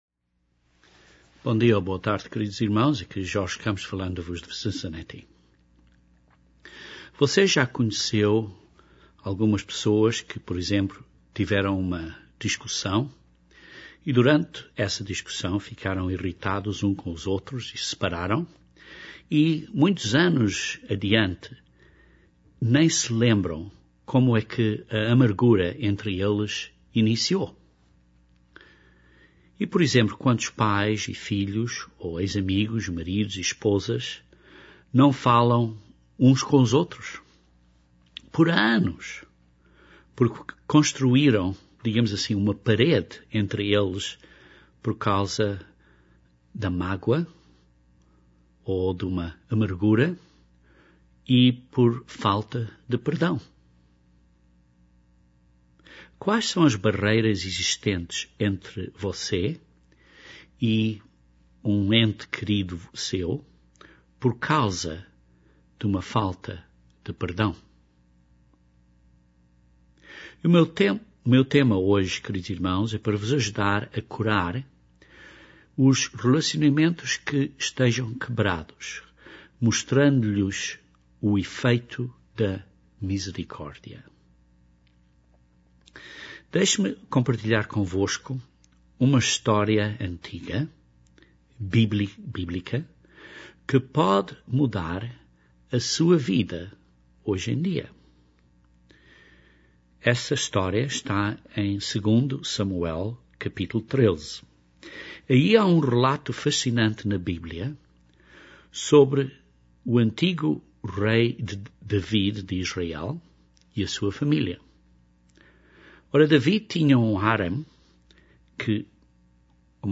Este sermão aborda este assunto e dá uns princípios bíblicos para o ajudar a curar relacionamentos quebrados através do efeito da misericórdia.